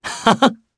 Clause-Vox_Happy2_jp.wav